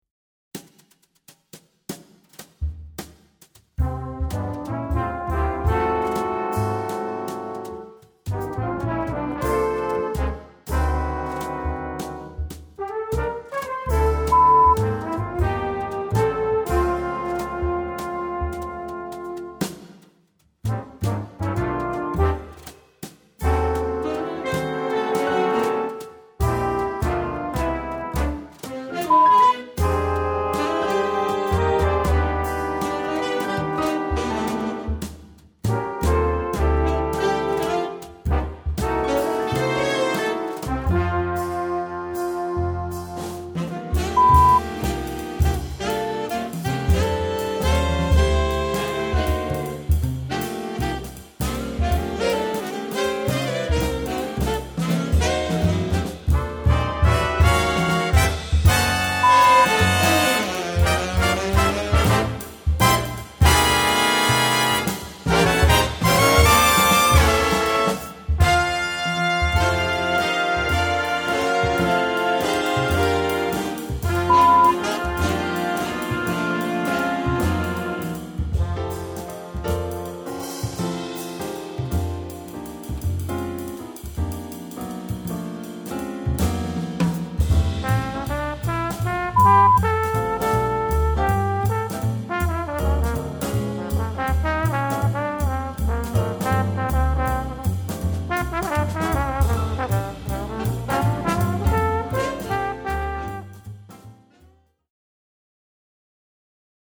Répertoire pour Jazz band - Jazz Band